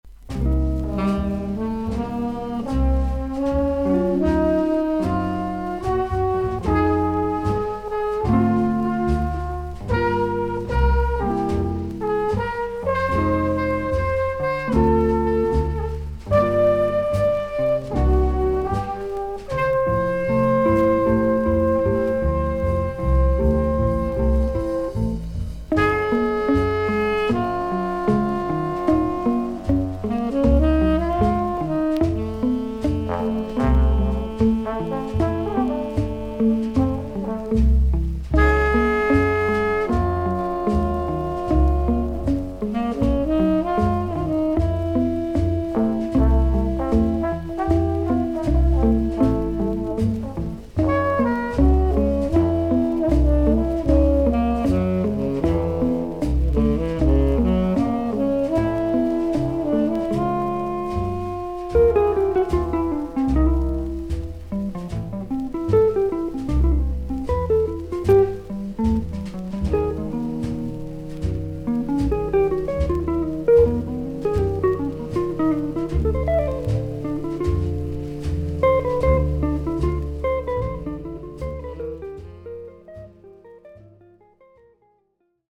ジャズ・サックス奏者/作曲家/美術家。
VG++〜VG+ 少々軽いパチノイズの箇所あり。クリアな音です。